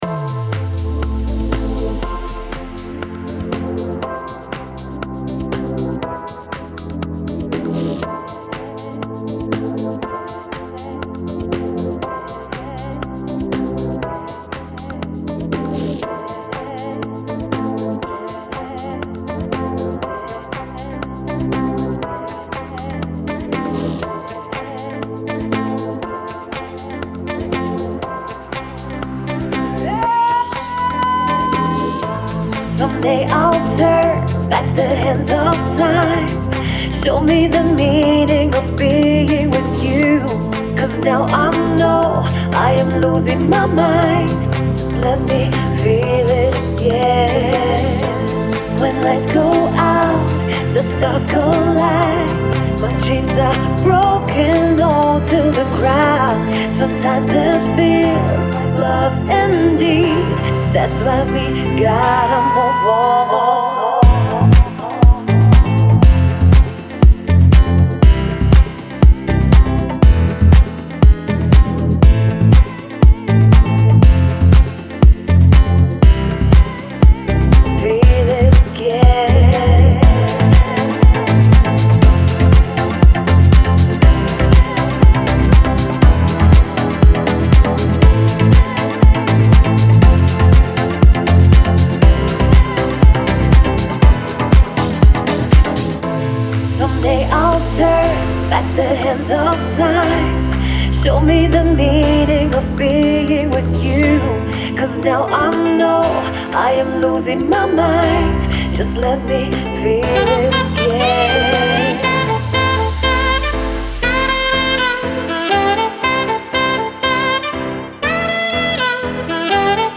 Deep House Teaser